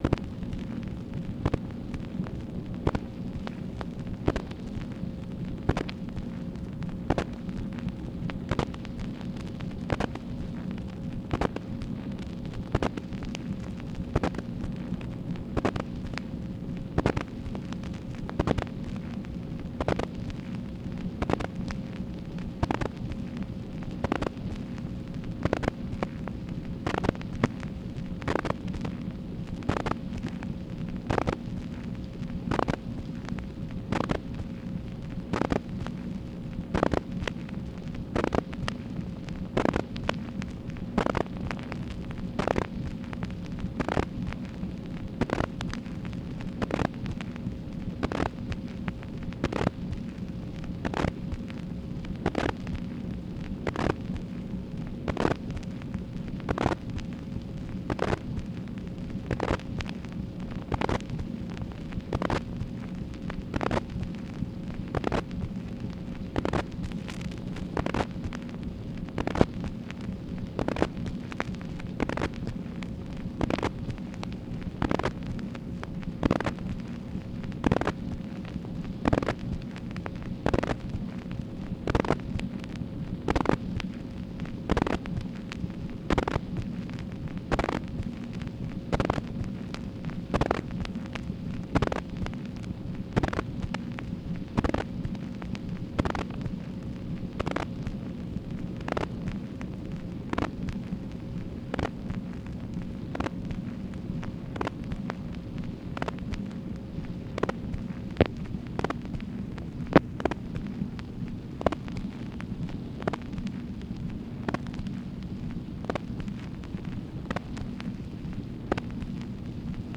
MACHINE NOISE, August 21, 1964
Secret White House Tapes | Lyndon B. Johnson Presidency